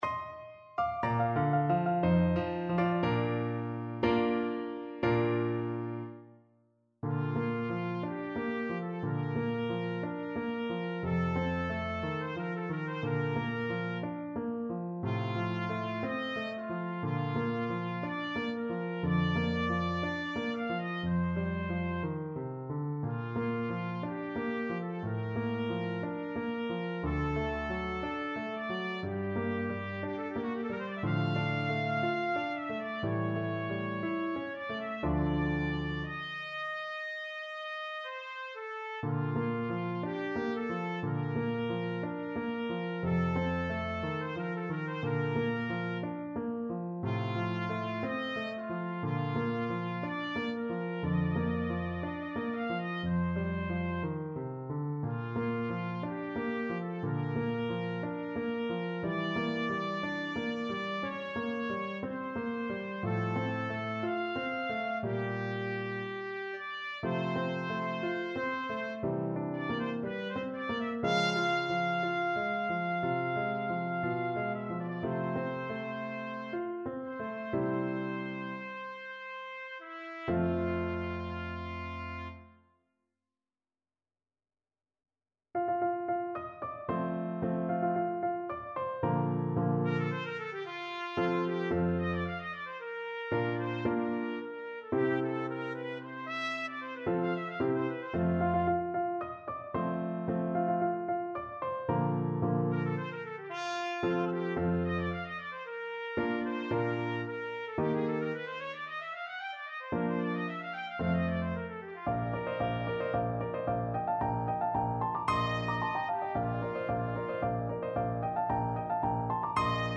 Classical Rossini, Giacchino Ecco, ridente in cielo from The Barber of Seville Trumpet version
Trumpet
2/4 (View more 2/4 Music)
Bb major (Sounding Pitch) C major (Trumpet in Bb) (View more Bb major Music for Trumpet )
Andante =c.60
Classical (View more Classical Trumpet Music)